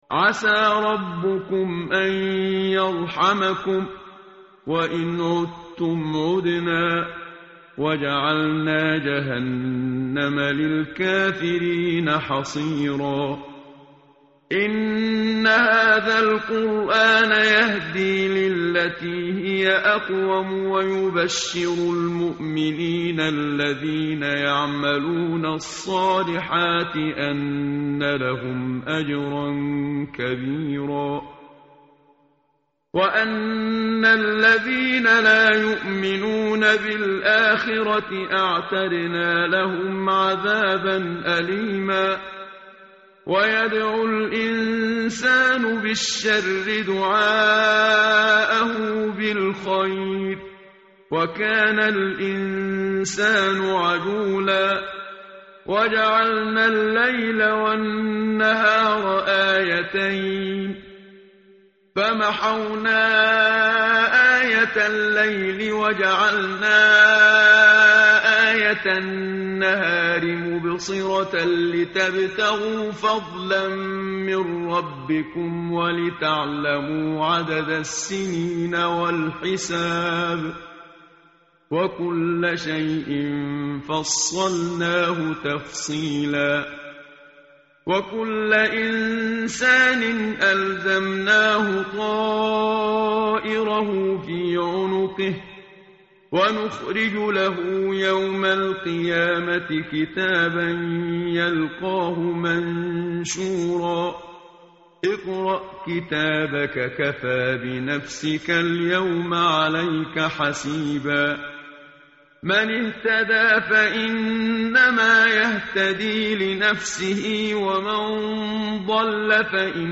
tartil_menshavi_page_283.mp3